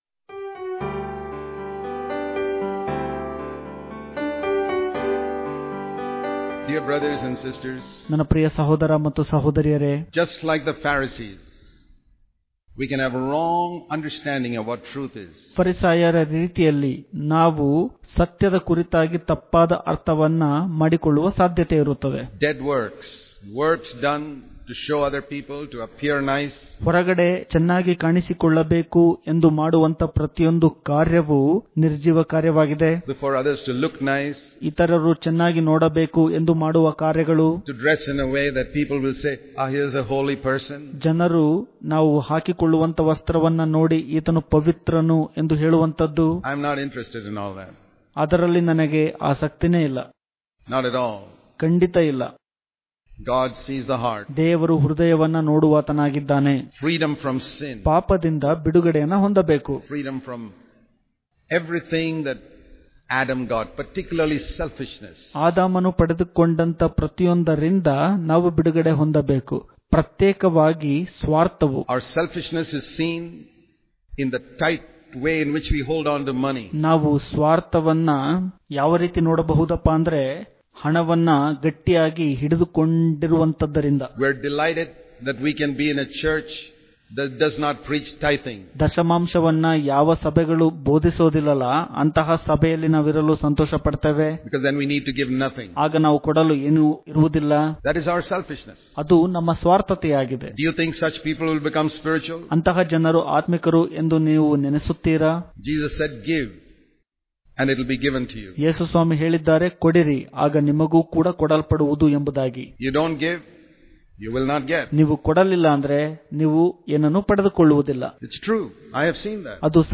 September 7 | Kannada Daily Devotion | Give, And It Will Be Given To You Daily Devotions